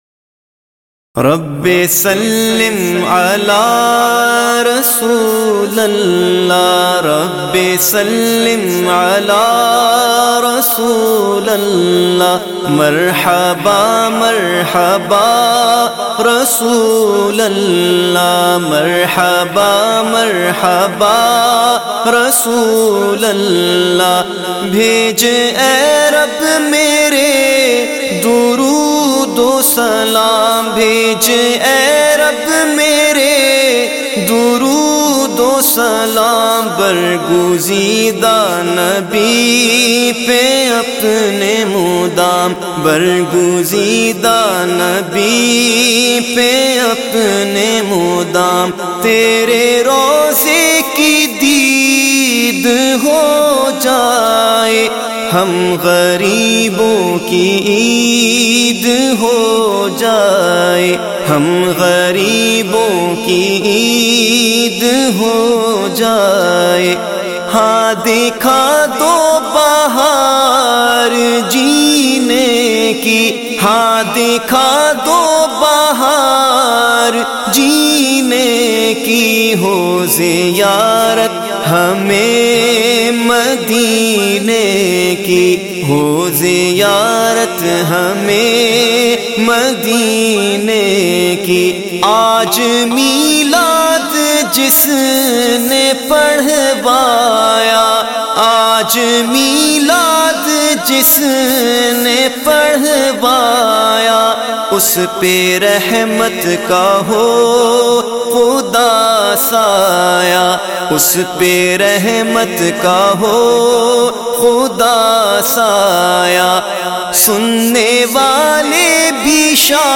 recited by famous Naat Khawan of Pakistan